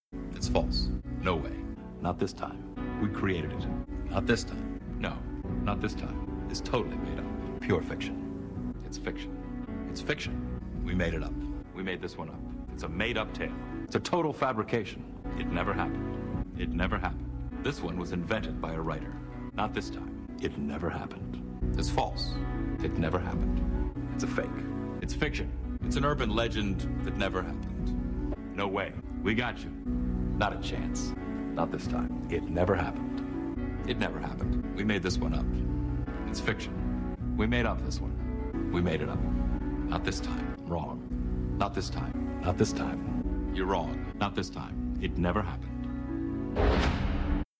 Jonathan Frakes tells you you are wrong for 47 seconds
Category: Comedians   Right: Personal